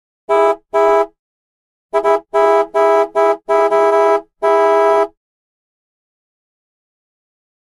Horn Honk; Chevy Truck Horn, Long And Short Honks. Close Perspective.